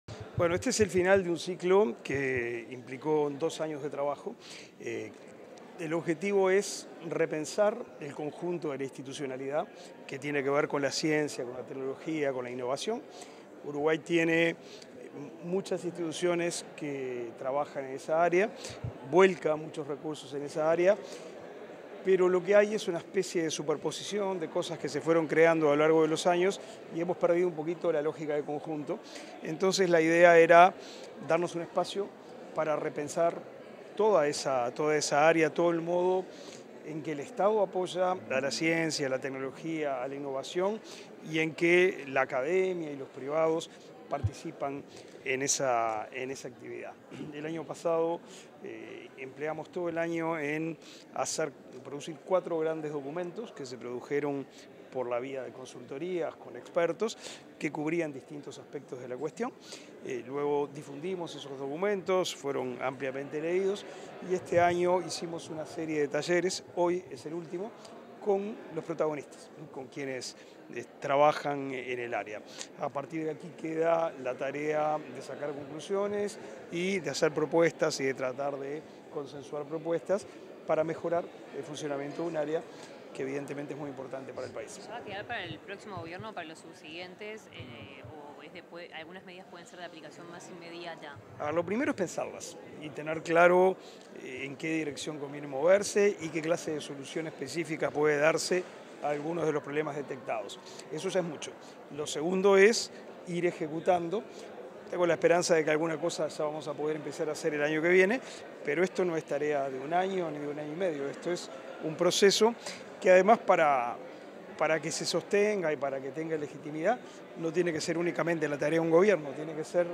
Declaraciones del ministro de Educación y Cultura, Pablo da Silveira
Declaraciones del ministro de Educación y Cultura, Pablo da Silveira 06/06/2023 Compartir Facebook X Copiar enlace WhatsApp LinkedIn Tras participar en el último taller del proceso de reordenamiento institucional del Área de Ciencia, Tecnología e Innovación, el ministro de Educación y Cultura, Pablo da Silveira, realizó declaraciones a la prensa.